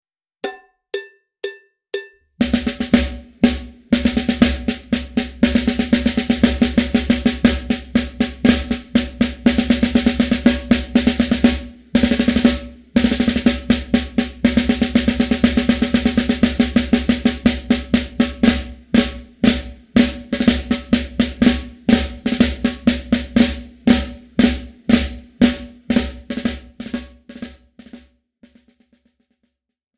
Besetzung: Schlagzeug